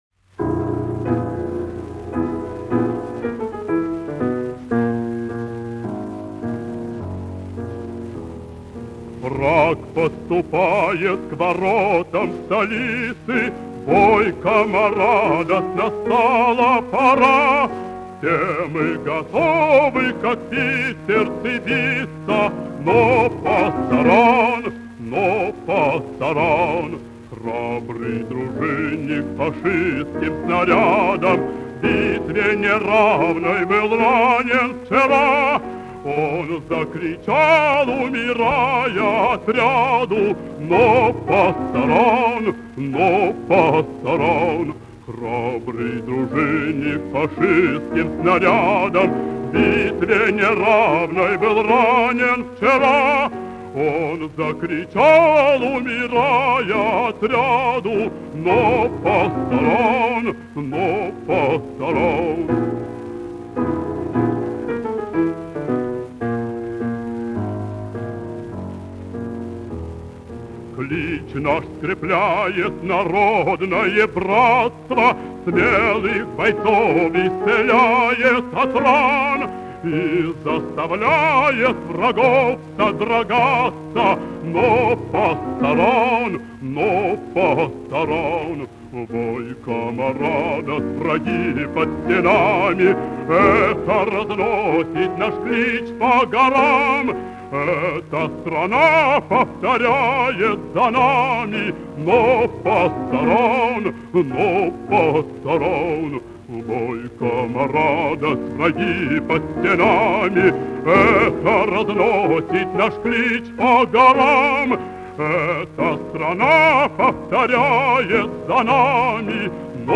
в сопр. ф-но